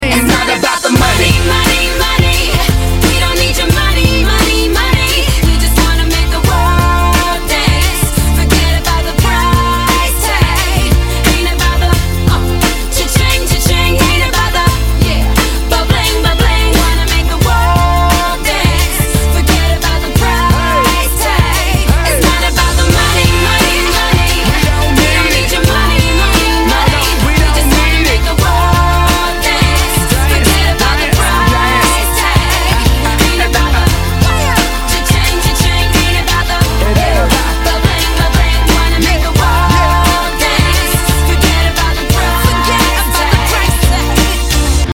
Назад в ~* Pop *~
Rock